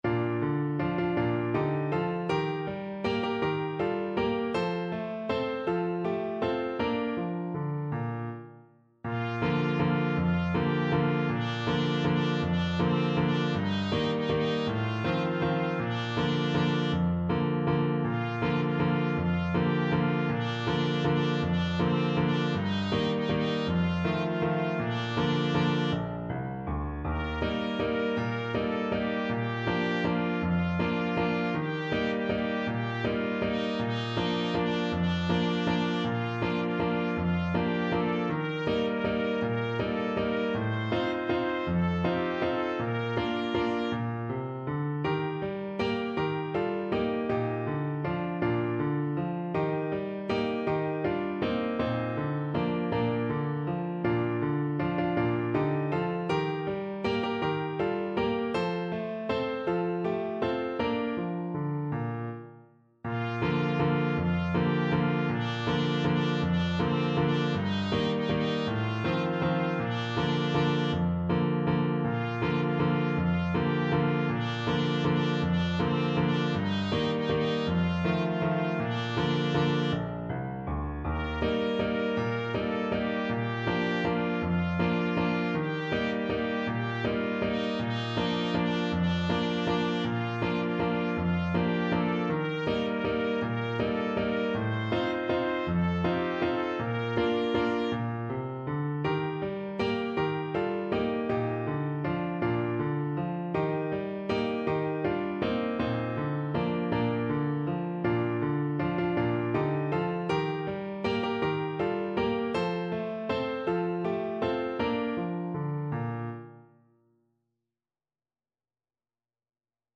Traditional Trad. Lustig ist das Zigeunerleben Trumpet version
Trumpet
3/4 (View more 3/4 Music)
One in a bar =c.160
Bb major (Sounding Pitch) C major (Trumpet in Bb) (View more Bb major Music for Trumpet )
C5-C6
Traditional (View more Traditional Trumpet Music)
Bavarian Music for Trumpet